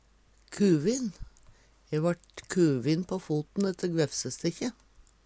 kuvin - Numedalsmål (en-US)